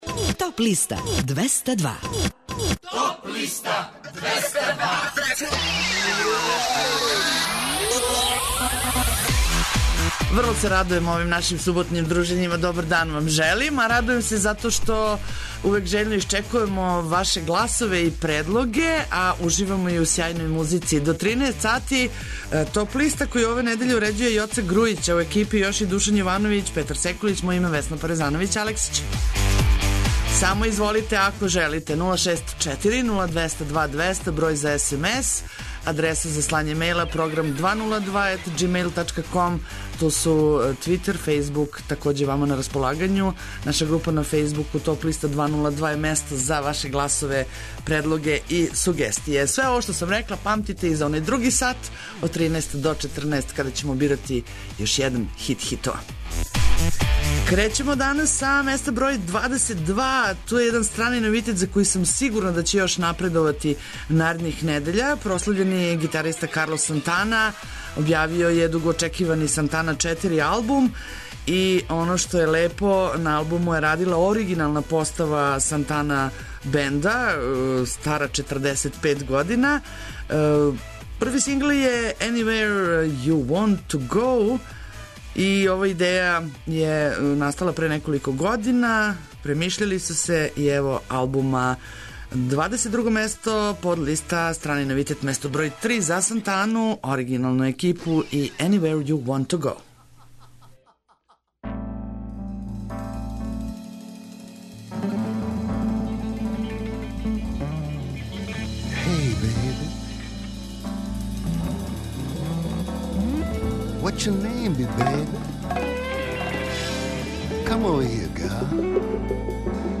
Најавићемо актуелне концерте у овом месецу, подсетићемо се шта се битно десило у историји музике у периоду од 2. до 6. маја. Емитоваћемо песме са подлиста лектире, обрада, домаћег и страног рока, филмске и инструменталне музике, попа, етно музике, блуза и џеза, као и класичне музике.